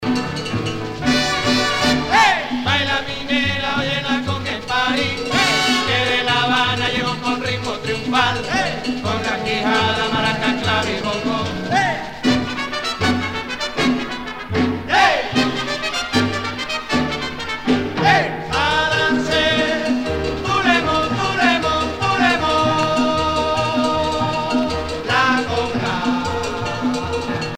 danse : congo